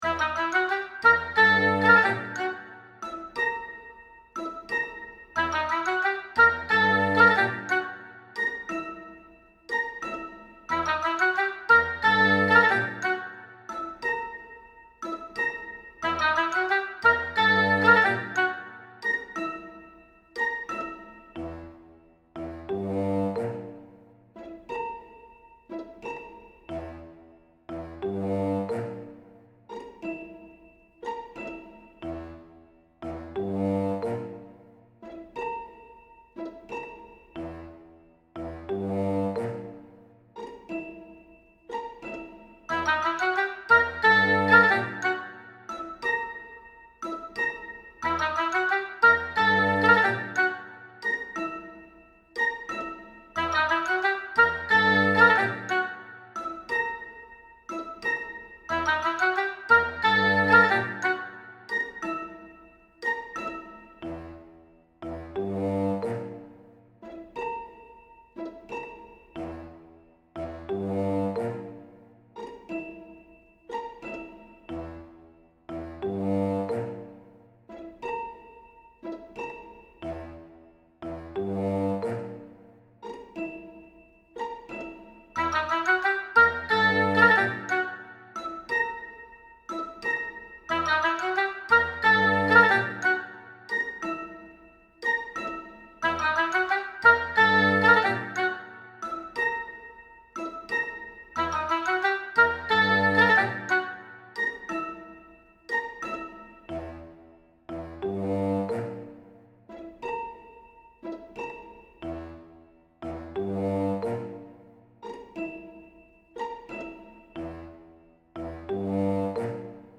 ゆるくてコミカルな雰囲気。